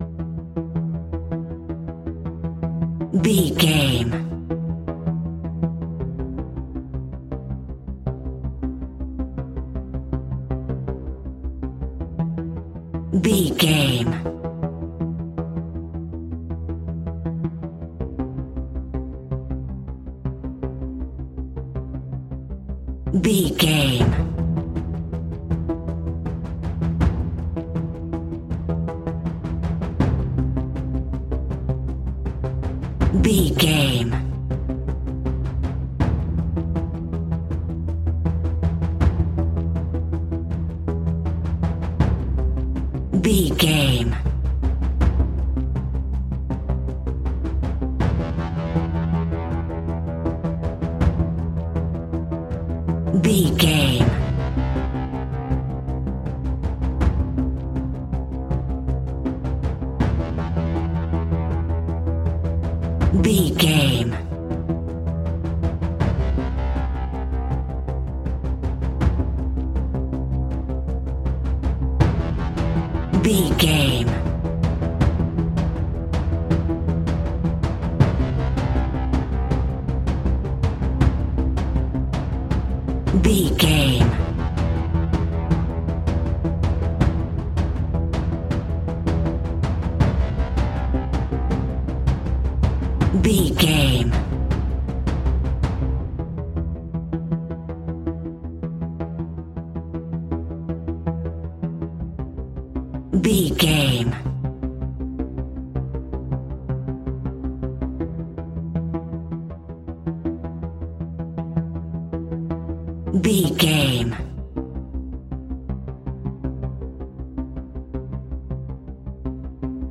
In-crescendo
Thriller
Aeolian/Minor
ominous
dark
haunting
eerie
synthesizer
percussion
mysterious
horror music